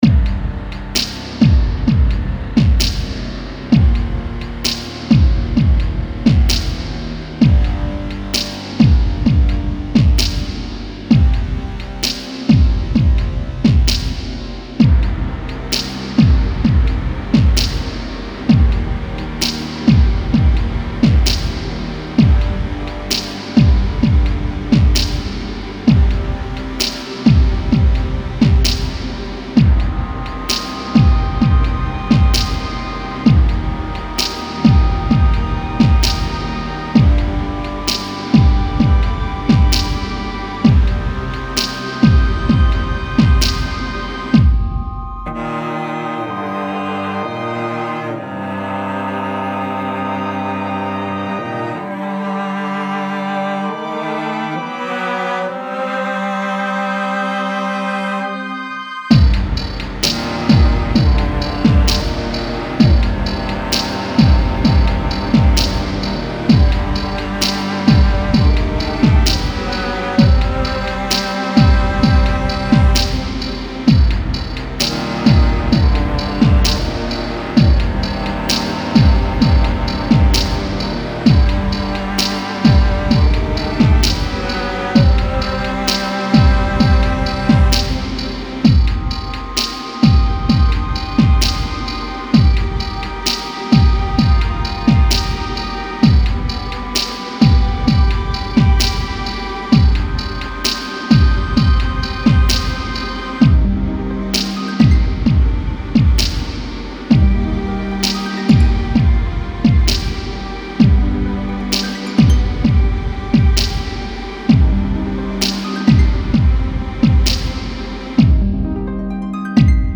Style Style EDM/Electronic, Soundtrack
Mood Mood Dark, Epic, Intense
Featured Featured Cello, Drums, Electric Guitar +2 more
BPM BPM 65